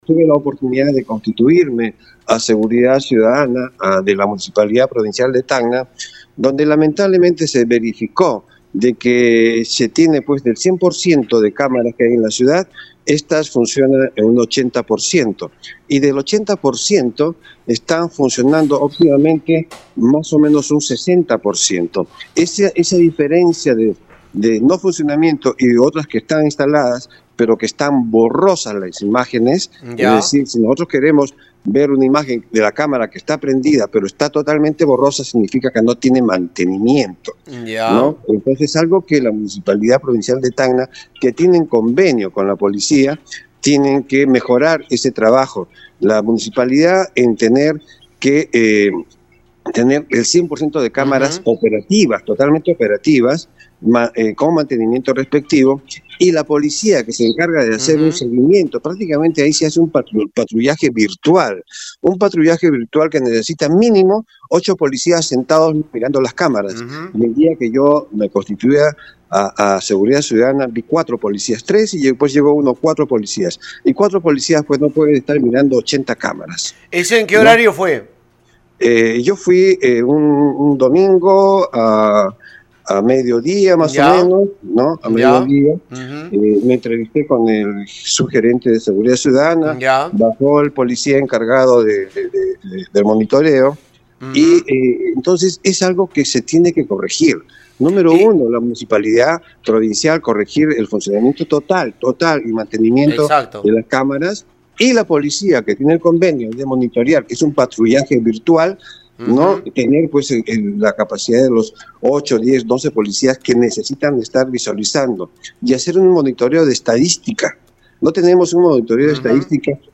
Vía Radio Uno, el fiscal Luis Vega Pilco instó a la Municipalidad Provincial de Tacna (MPT) corregir el sistema de video vigilancia empleado en la lucha contra la delincuencia pues en reciente visita a instalaciones de Seguridad Ciudadana constató que solo el 60% de cámaras están operativas, y el personal empleado es escaso siendo necesario mínimo 10 agentes en vigilancia.
fiscal-jose-luis-vega-pilco.mp3